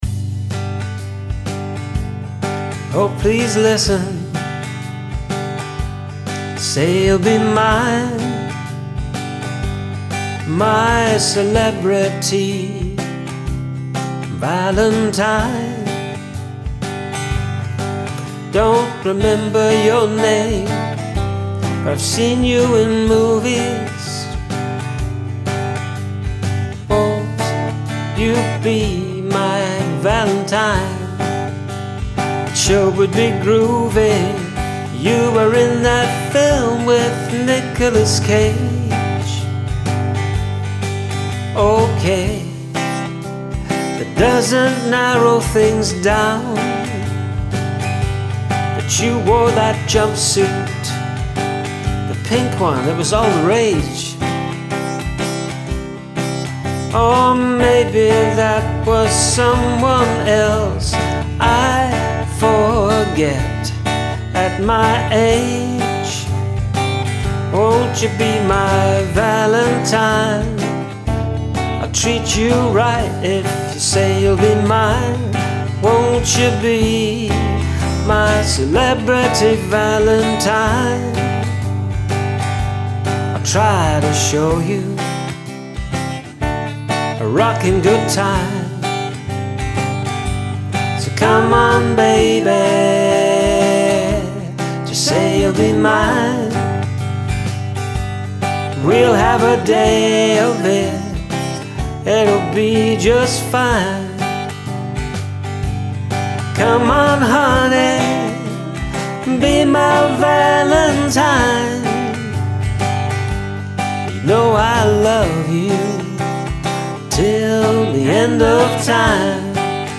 Nice groove, nice playing.
Oooh, lovely harmonies
I like the sort of folkcountry stylings, too.